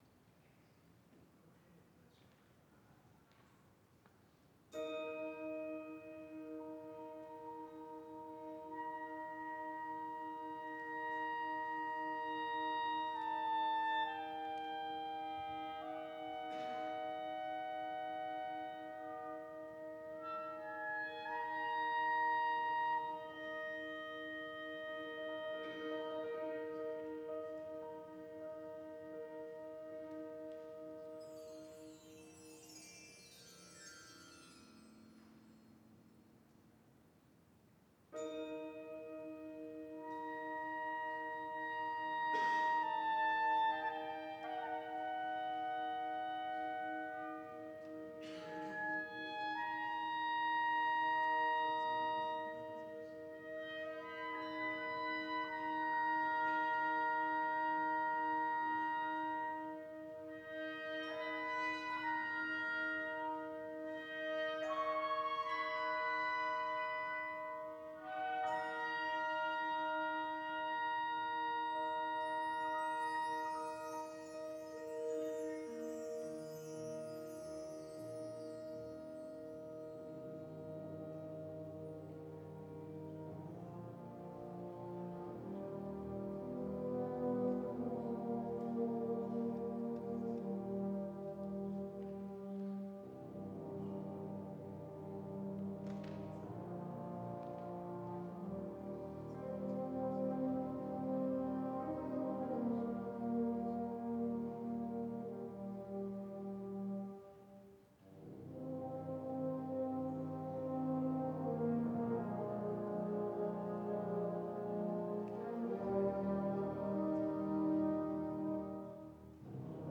Congratulations to the Sherwood Wind Ensemble for winning the Three Rivers League Symphonic Band Championship! Our students should be very proud of their musical performance & accomplishment.
03-sherwood-hs-wind-ensemble-trl-2.m4a